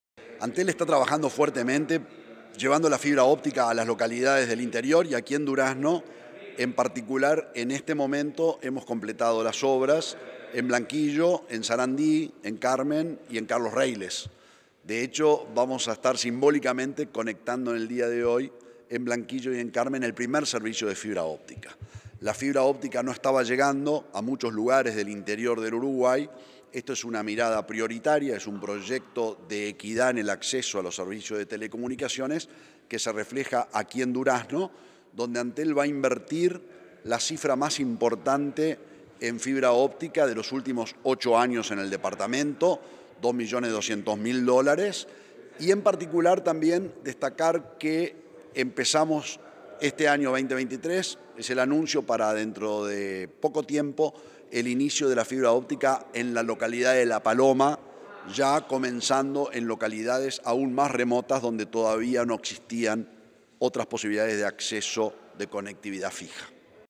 Entrevista al presidente de Antel, Gabriel Gurméndez